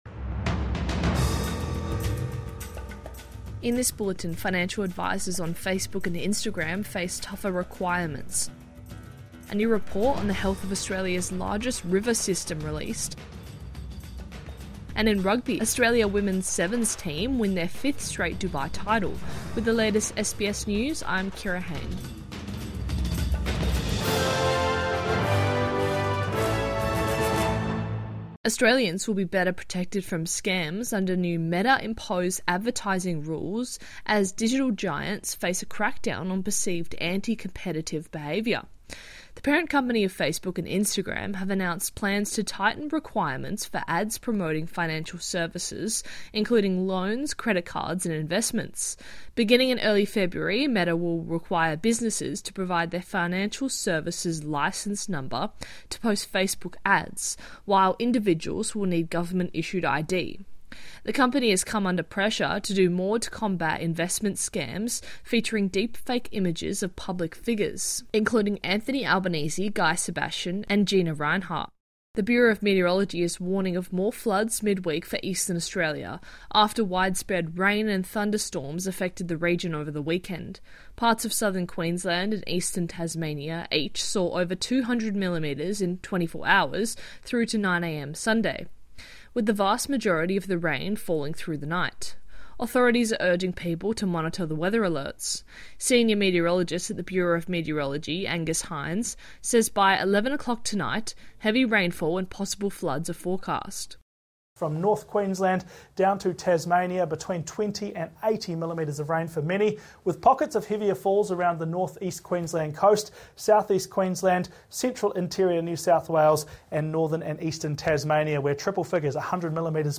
Midday News Bulletin 2 December 2024